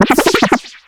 Cri de Pérégrain dans Pokémon X et Y.